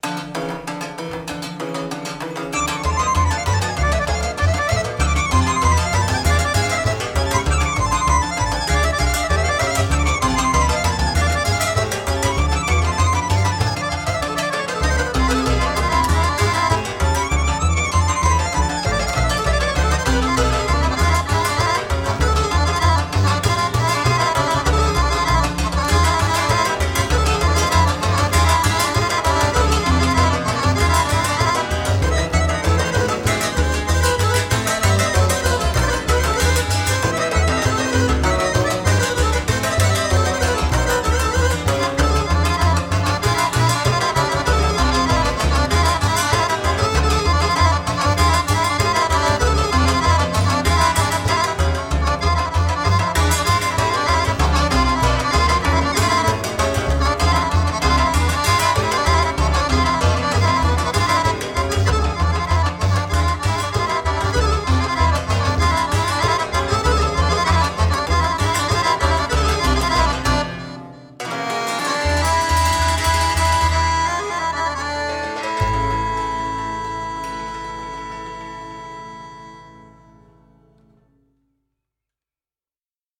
Tabernacle Folk Festival , April 2013.